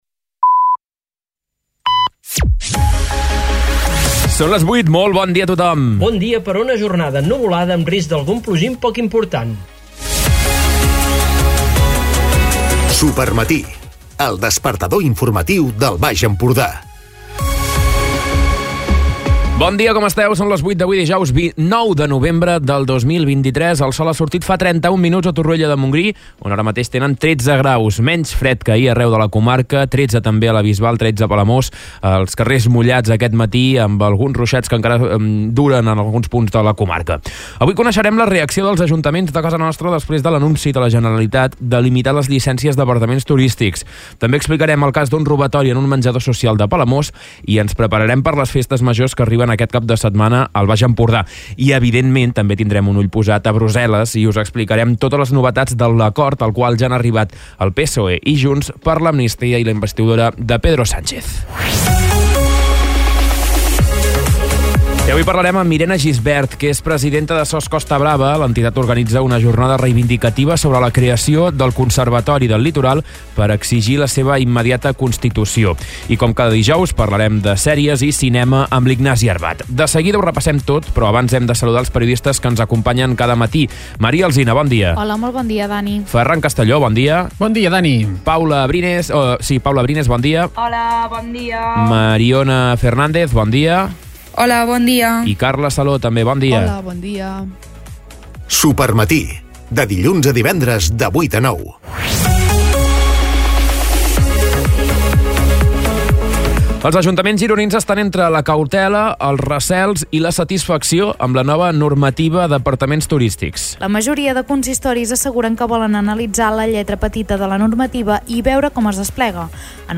Escolta l'informatiu d'aquest dijous